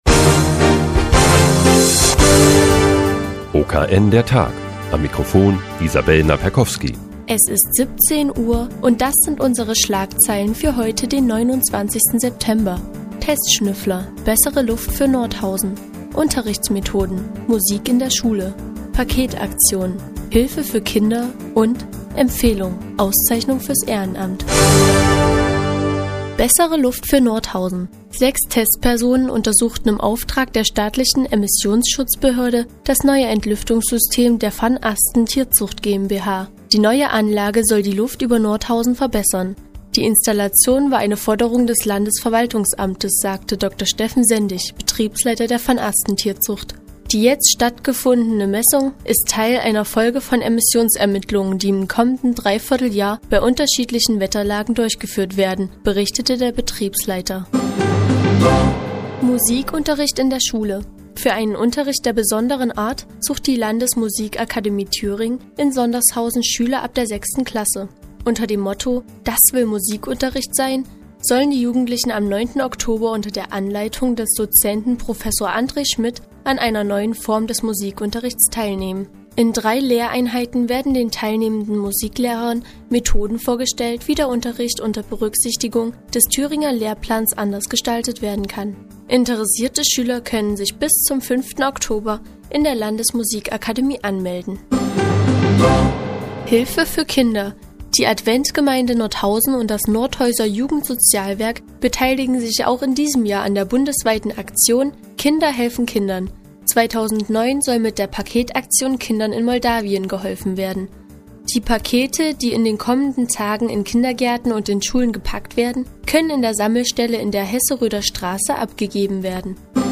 Die tägliche Nachrichtensendung des OKN ist nun auch in der nnz zu hören. Heute geht es unter anderem um den Musikunterricht und bessere Luft für Nordhäuser.